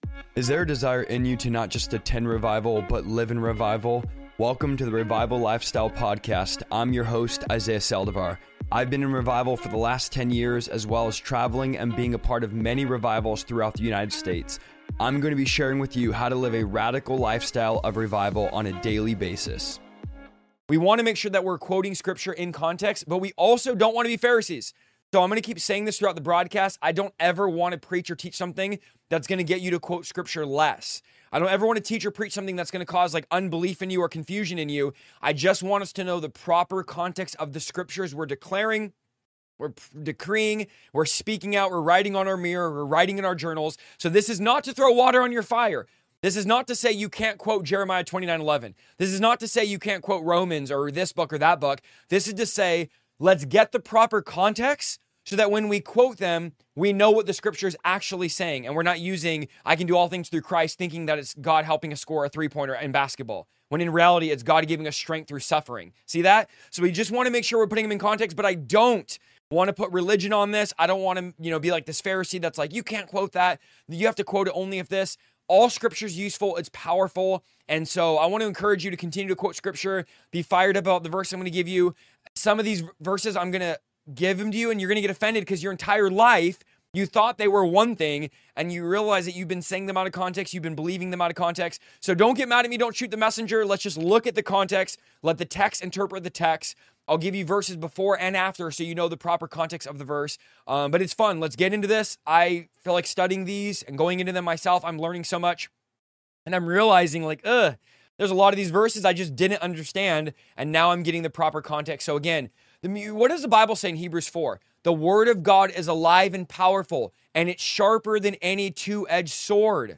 Whether you're new to studying the Bible or a long-time believer, this teaching will help you grow in discernment, sharpen your understanding, and fall in love with contextual Bible study.